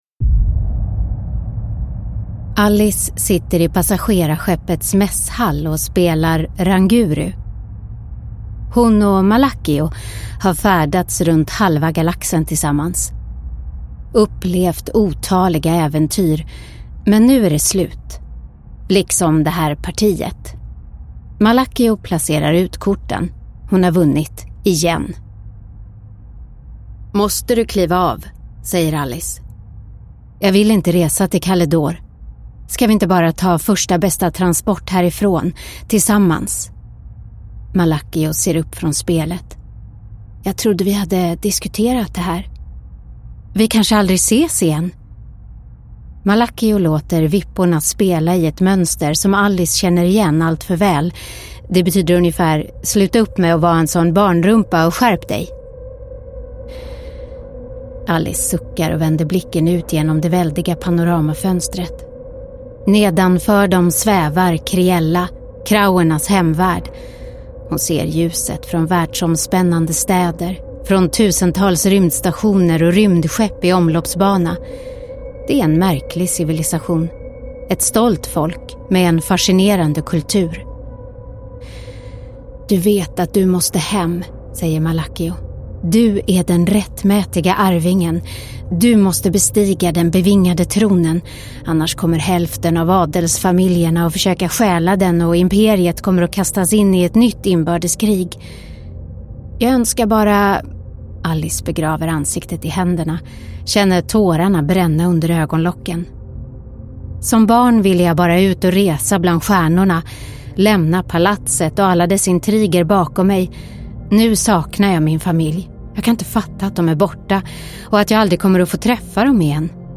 Fängelseflykten – Ljudbok – Laddas ner